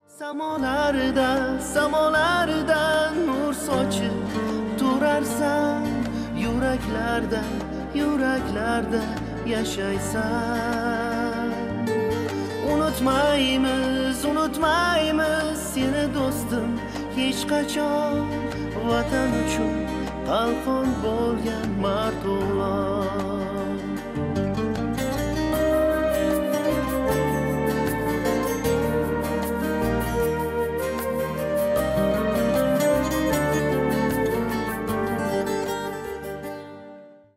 Узбекские # грустные